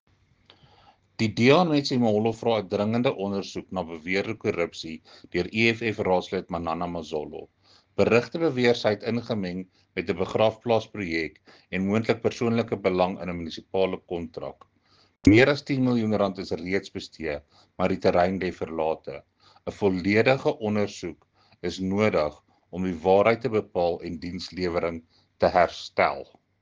Afrikaans soundbite by Cllr Jacques Barnard.